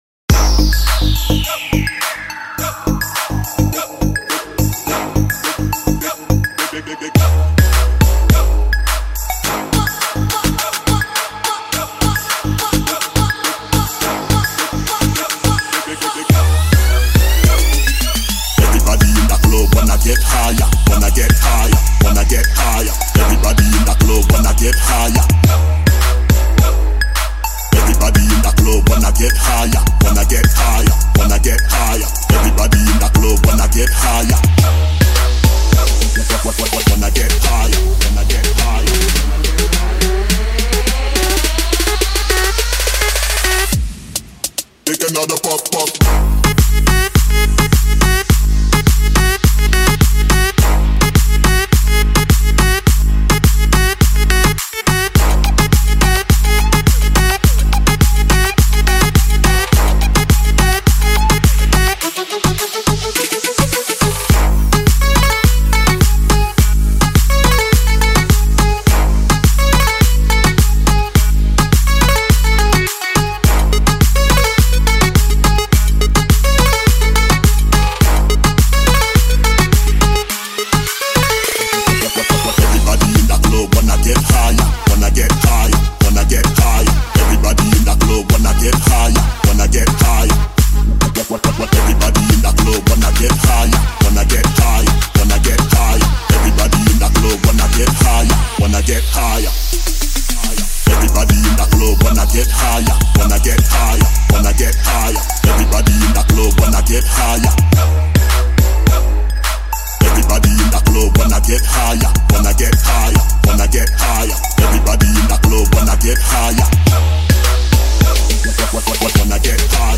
Bass Boosted Trap 2025
Slowed + Reverb | Bass Boosted